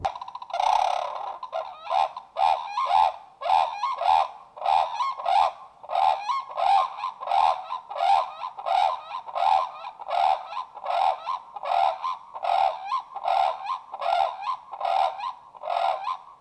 Unison Call | A duet performed by a pair, to strengthen their bond and protect their territory.
Brolga-Unison.mp3